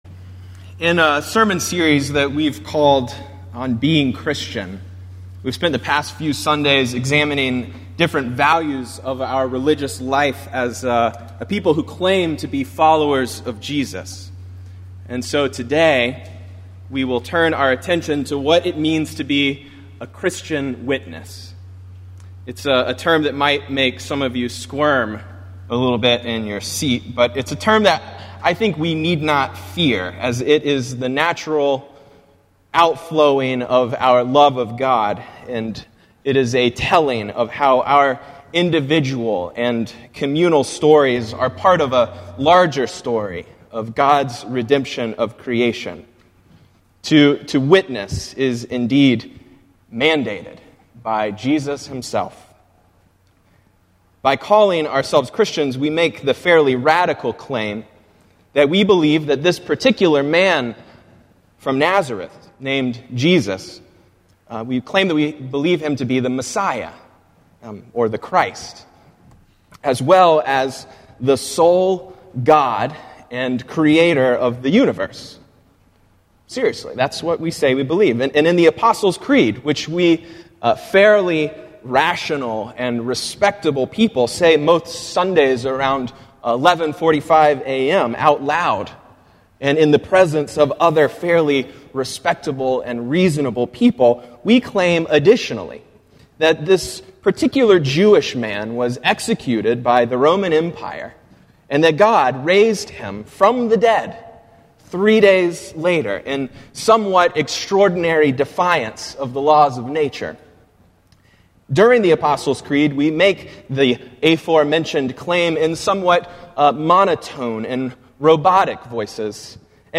Morningside Presbyterian Church - Atlanta, GA: Sermons: On Being Christians: The Christian Witness